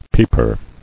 [peep·er]